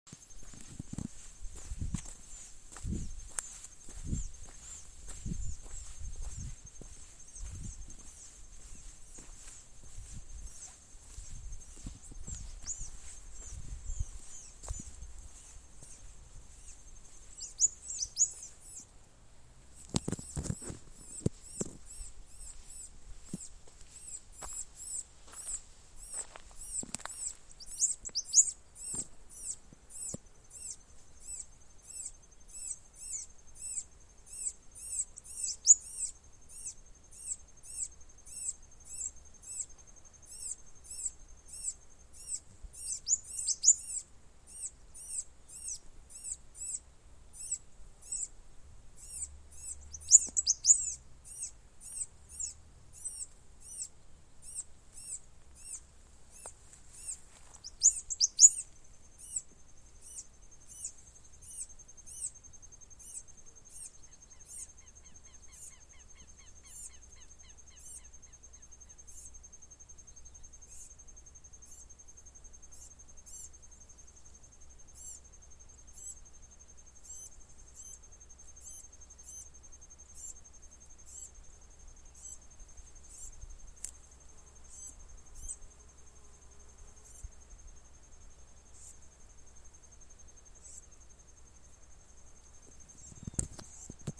Pampa Finch (Embernagra platensis)
Country: Argentina
Location or protected area: Santa María
Condition: Wild
Certainty: Observed, Recorded vocal